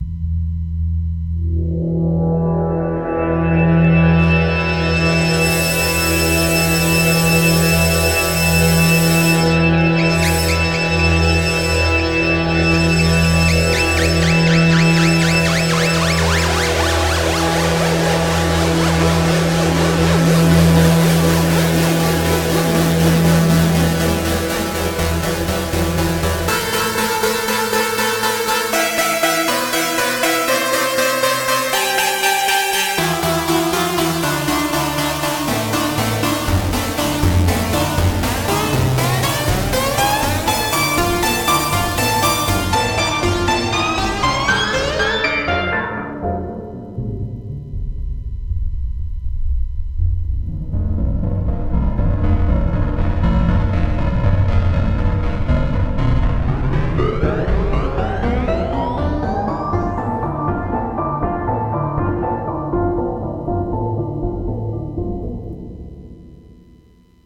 PitchADSR1-Part2 (DEMO Audio)